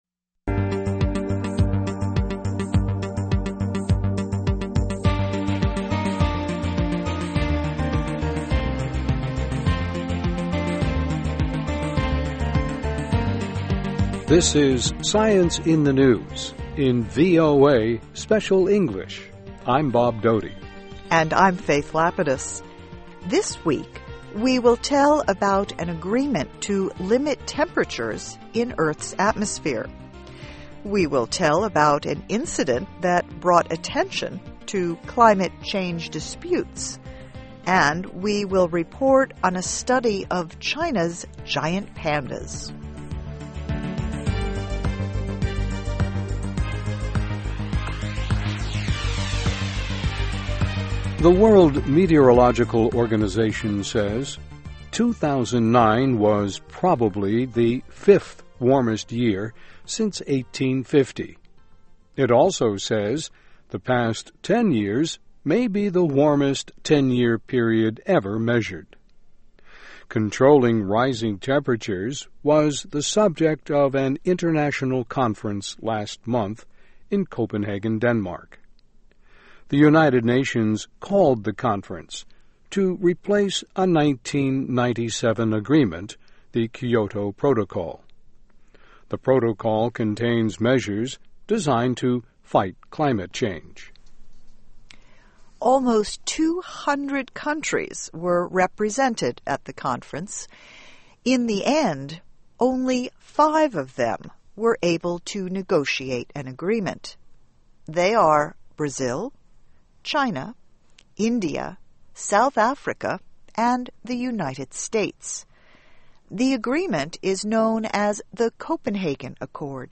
This is SCIENCE IN THE NEWS in VOA Special English.